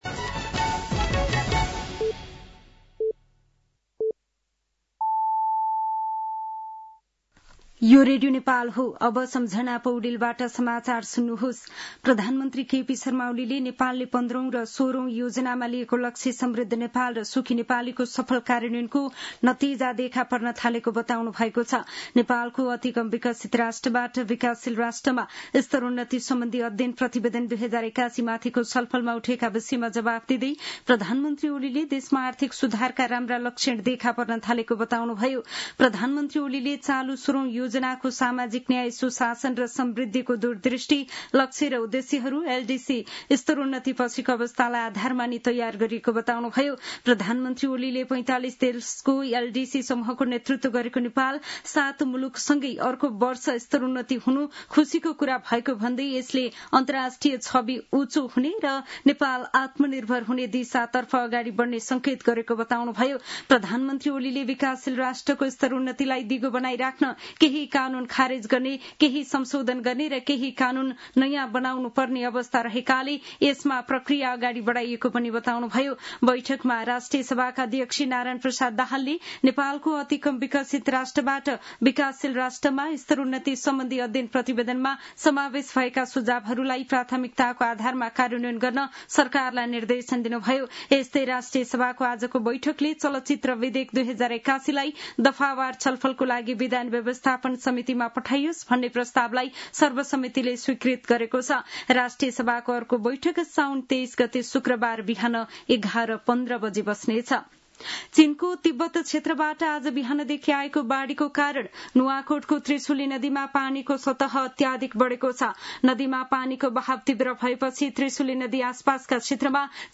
साँझ ५ बजेको नेपाली समाचार : १४ साउन , २०८२
5-pm-nepali-news-4-14.mp3